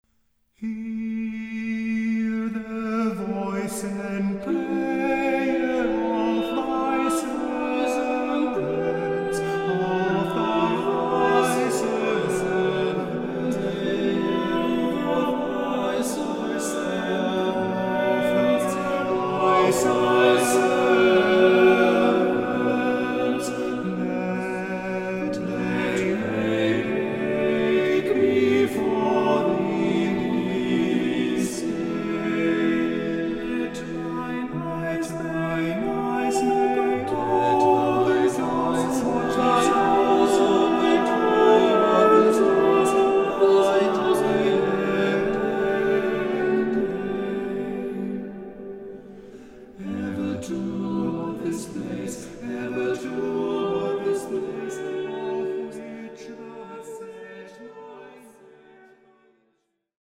Shop / CDs / Vokal